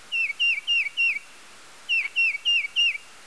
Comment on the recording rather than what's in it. This one was recorded on February 26, 1996 in Smithtown, New York.